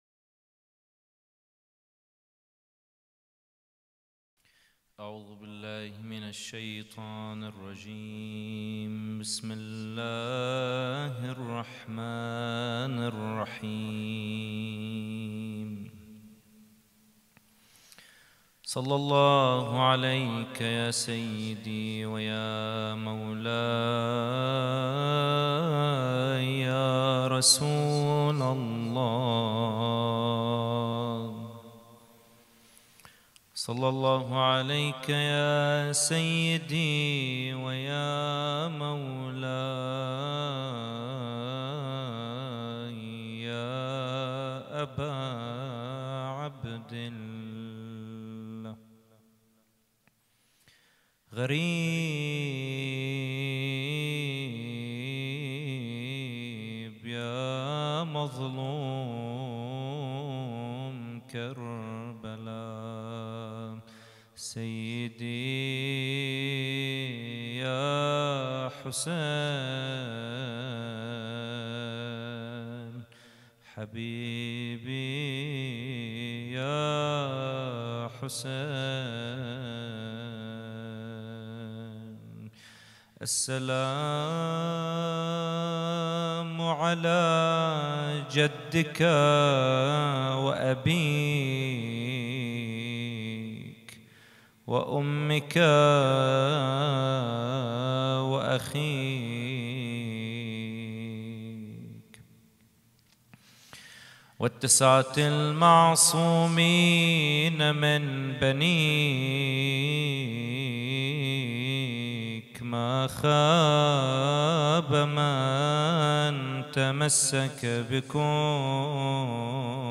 محاضرة
إحياء ثاني ليلة محرم 1442 ه.ق